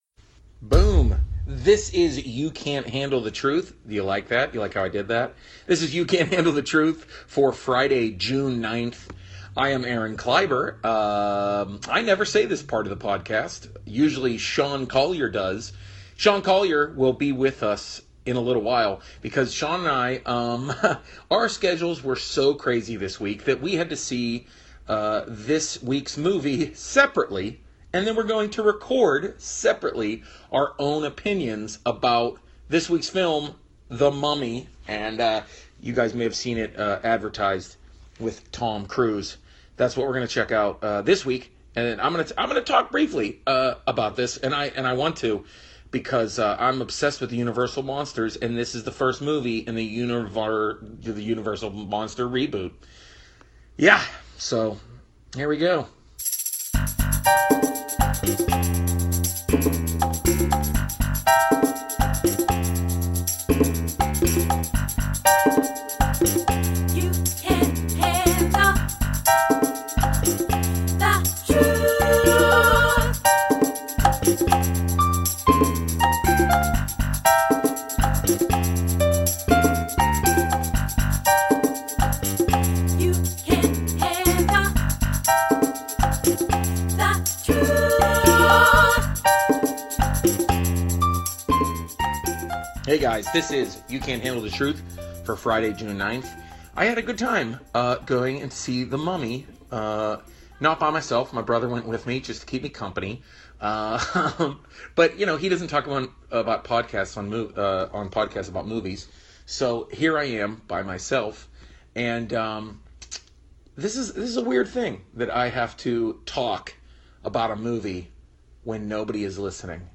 Every week we bring you reviews of movies, recorded immediately after an advance screening.